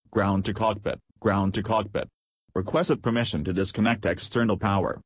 mechCall.wav